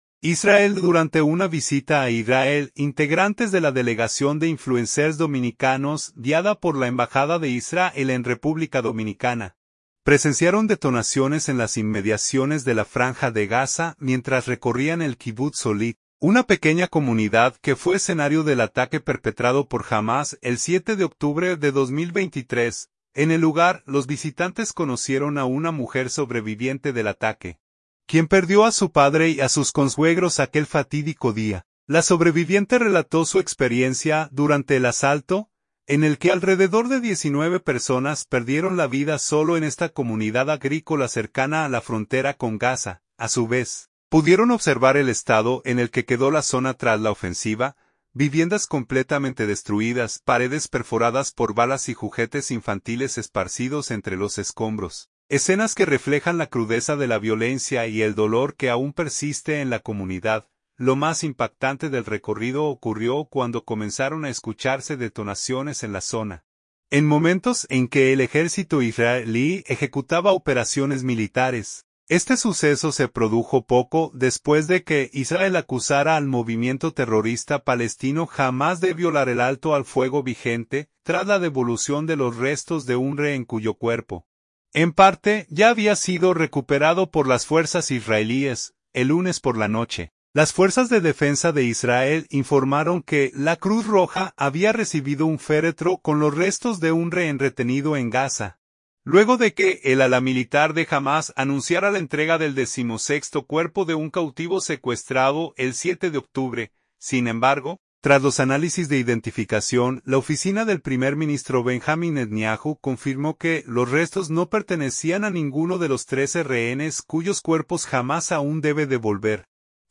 Delegación dominicana presencia detonaciones en la Franja de Gaza durante visita al Kibutz Holit
Lo más impactante del recorrido ocurrió cuando comenzaron a escucharse detonaciones en la zona, en momentos en que el ejército israelí ejecutaba operaciones militares.